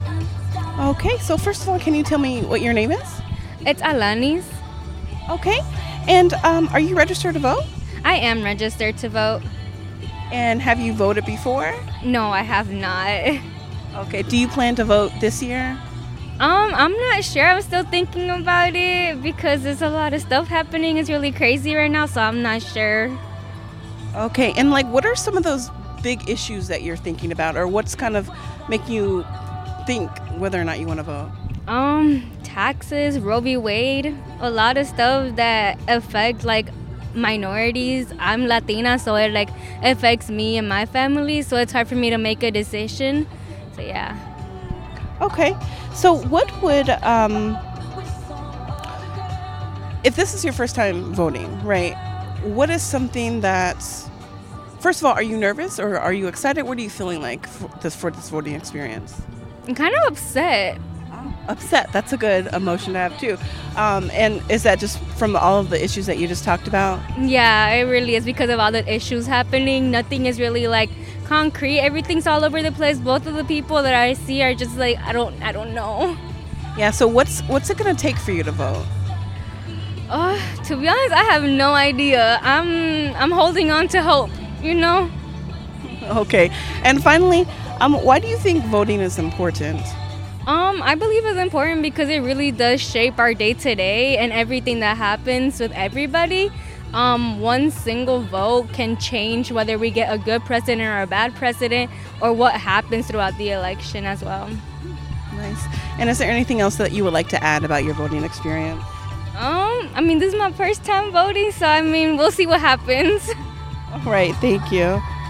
Wisco Soundoff Day 2